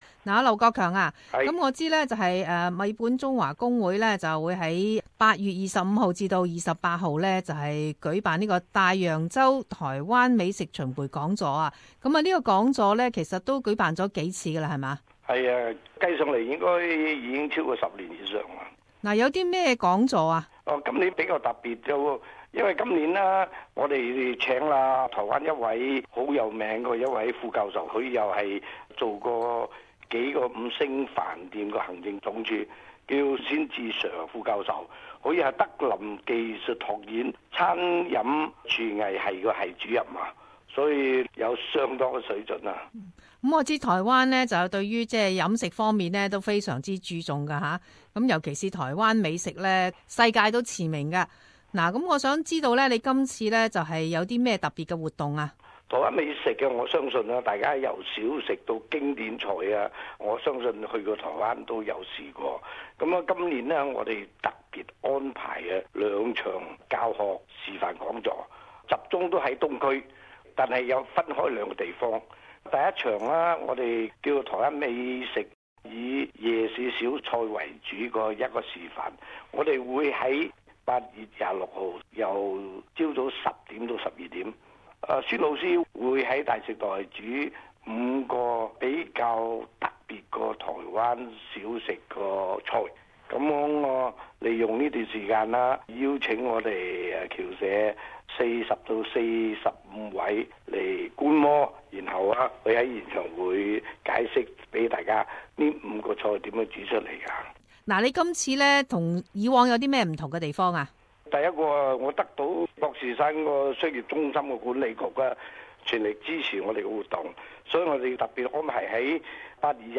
【社團專訪】台灣名厨示範十大名菜